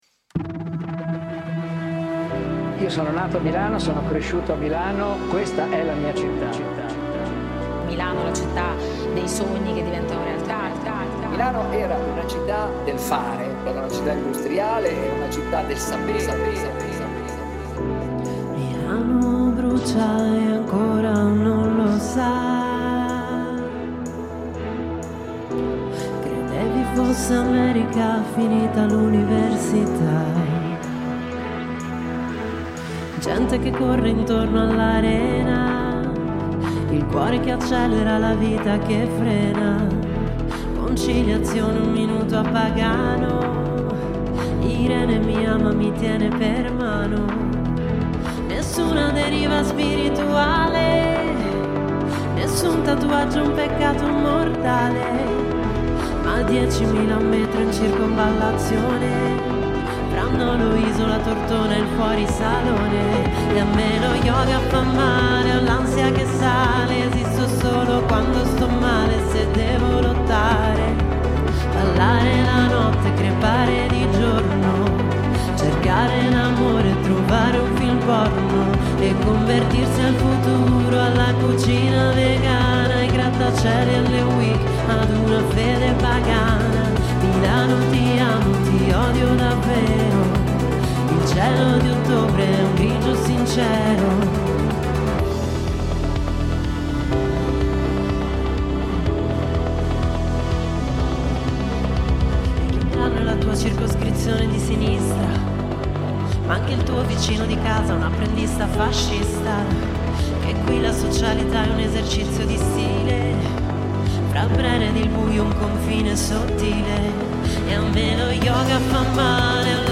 suonarci alcuni pezzi dal vivo
Ascolta l’intervista e il MiniLive